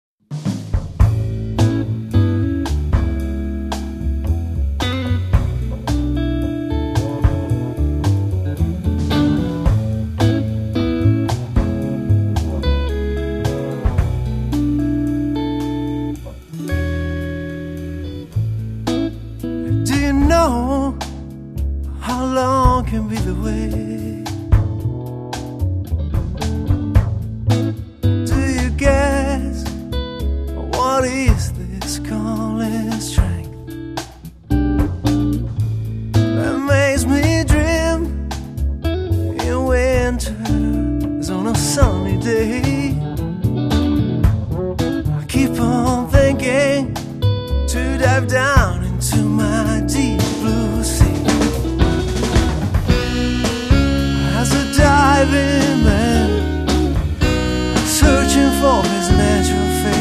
electric guitar & voice
double bass
drums & percussion
alto saxophone